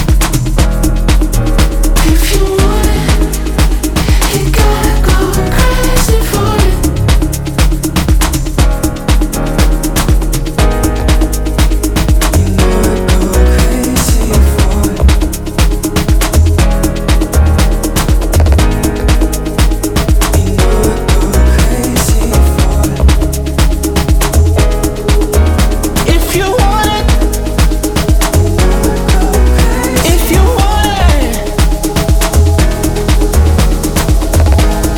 Electronic
Жанр: Электроника